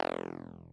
arcade game jump Mario rpg-game spring sound effect free sound royalty free Gaming